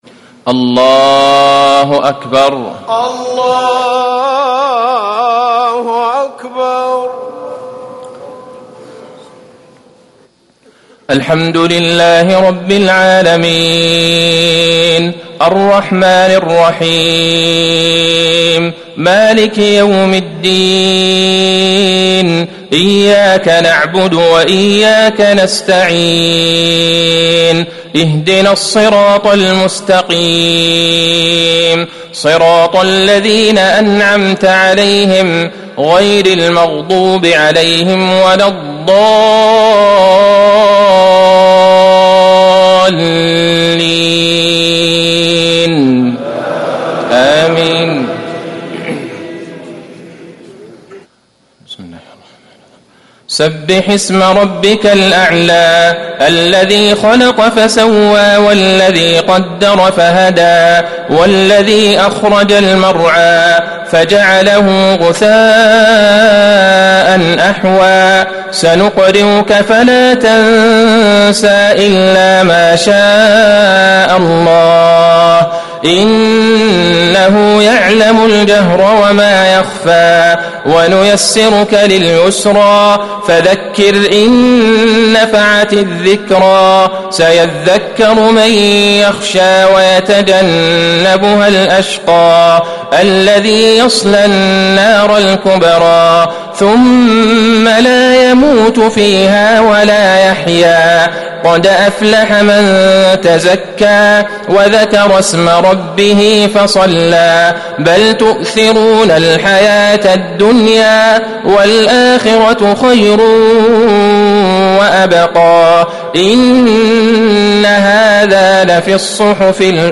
صلاة الجمعة 3-6-1440هـ سورتي الأعلى و الغاشية | Jumu’ah 8-2-2019 prayer from Surah Al-a’ala & Al-Ghashiya > 1440 🕌 > الفروض - تلاوات الحرمين